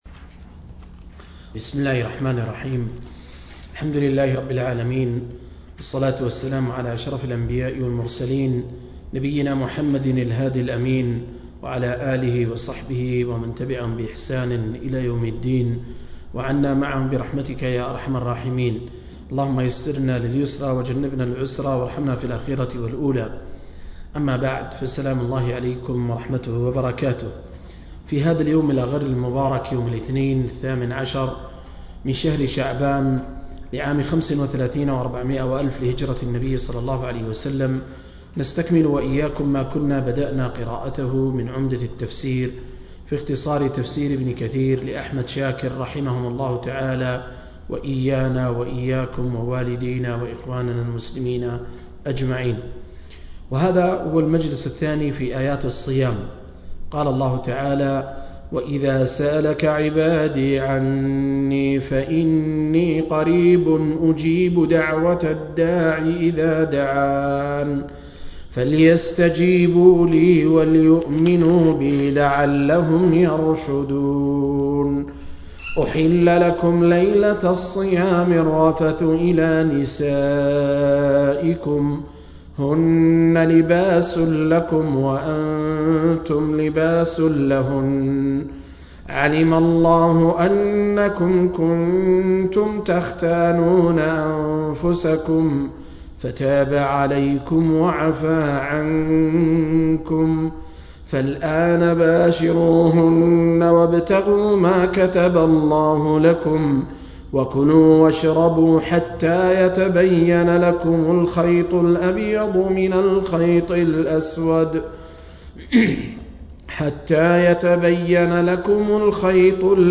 037- عمدة التفسير عن الحافظ ابن كثير – قراءة وتعليق – تفسير سورة البقرة (الآيات 187-186)